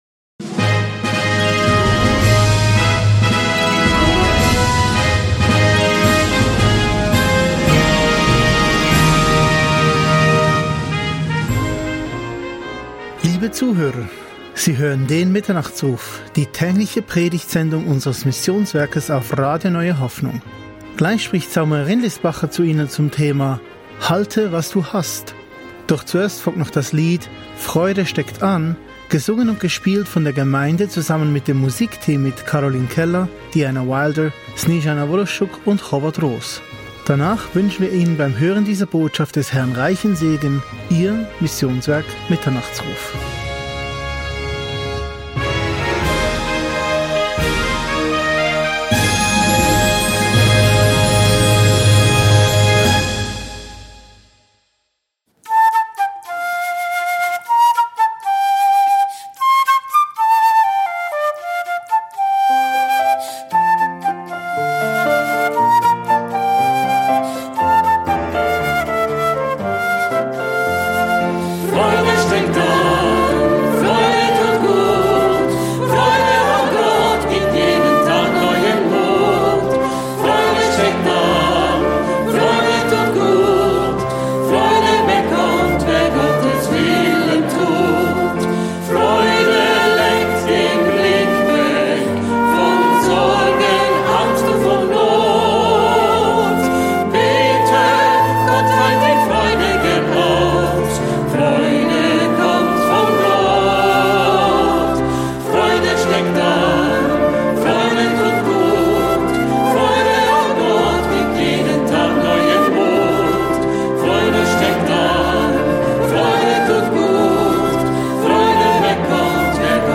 Dies ist der Podcast unseres Hauptprogramms - der täglichen Predigtsendung um 8 und um 20 Uhr (ausser Freitags).